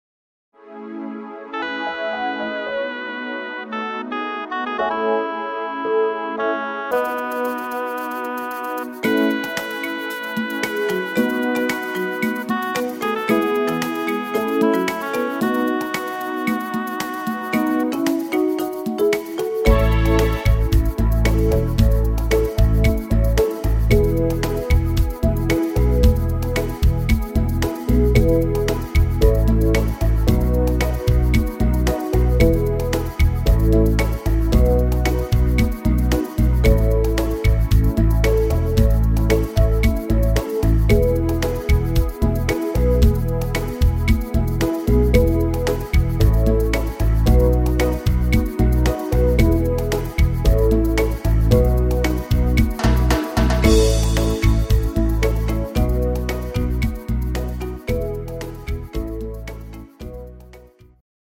instr. Panflöte